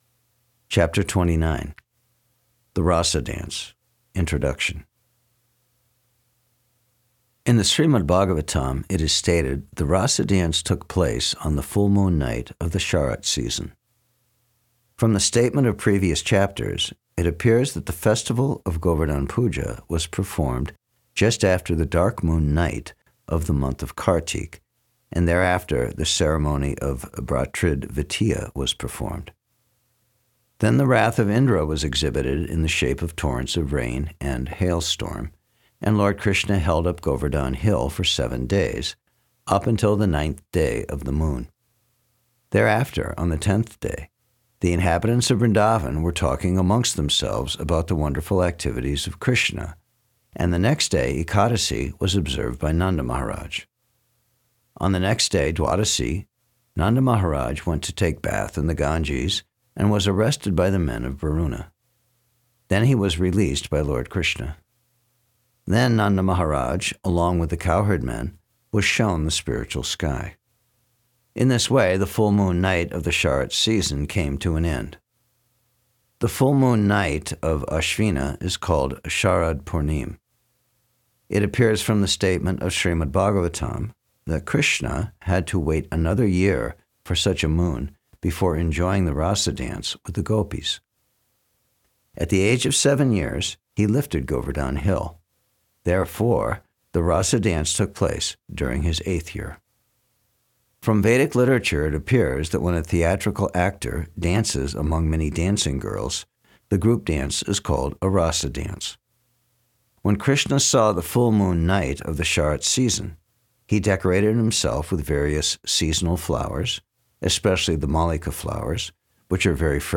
Thank you your wonderful effort and the narrations are beautifully done.